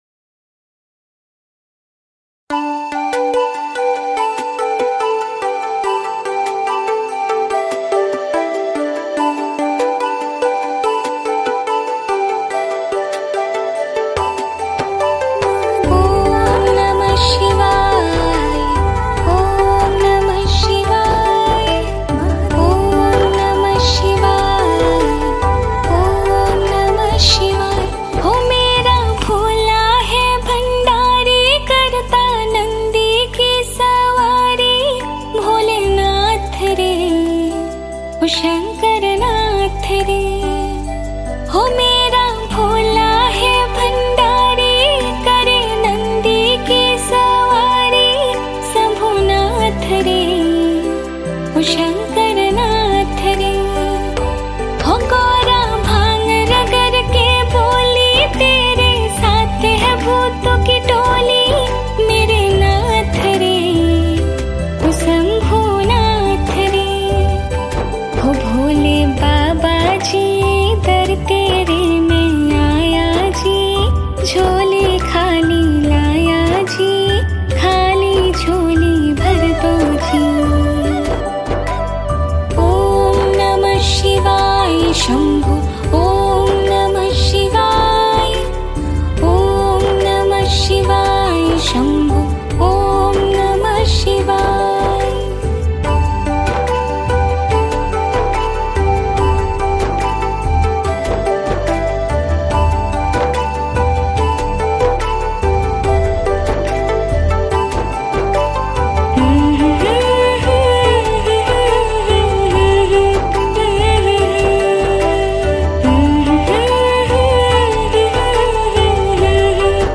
Category New Cover Mp3 Songs 2020 Singer(s